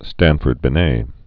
(stănfərd-bĭ-nā)